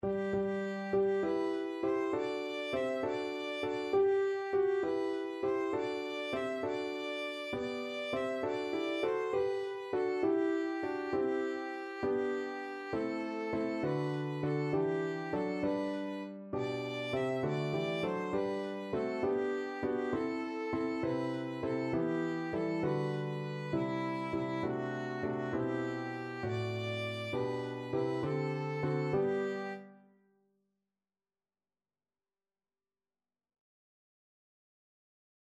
Christmas Christmas Violin Sheet Music Good Christian Men, Rejoice
Violin
G major (Sounding Pitch) (View more G major Music for Violin )
6/8 (View more 6/8 Music)
Classical (View more Classical Violin Music)